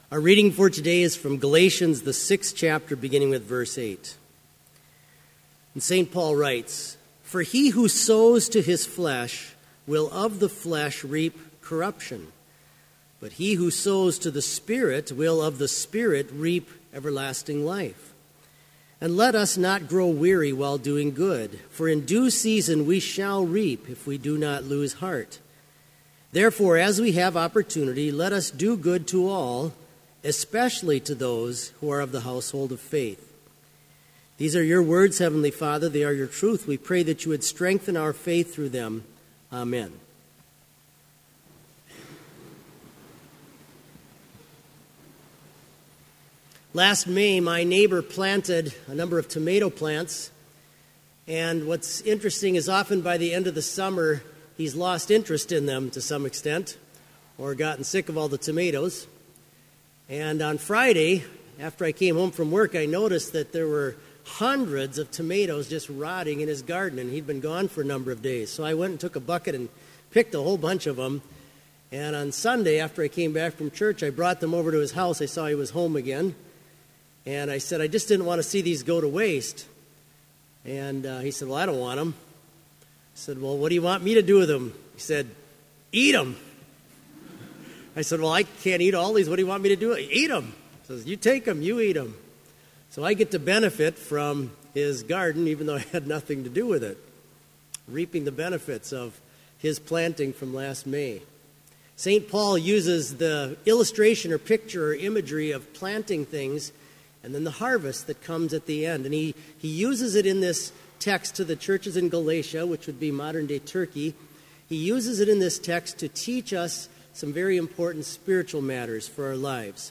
Complete service audio for Chapel - August 29, 2016